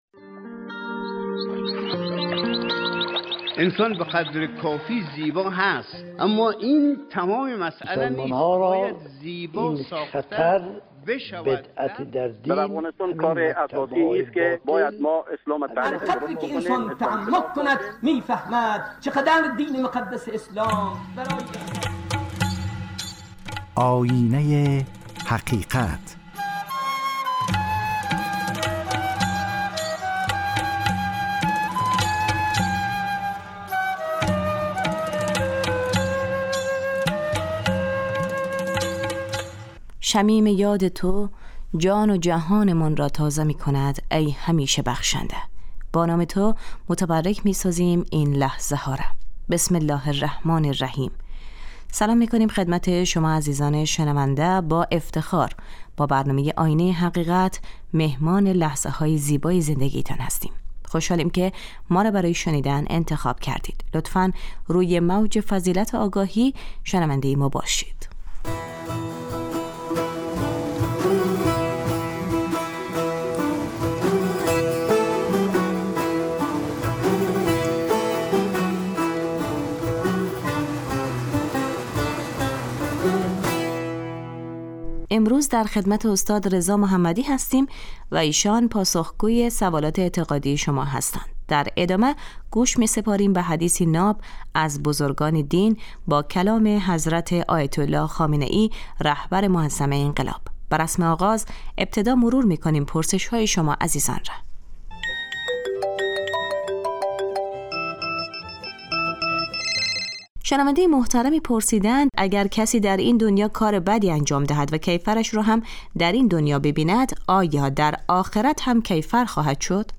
پرسش و پاسخ های اعتقادی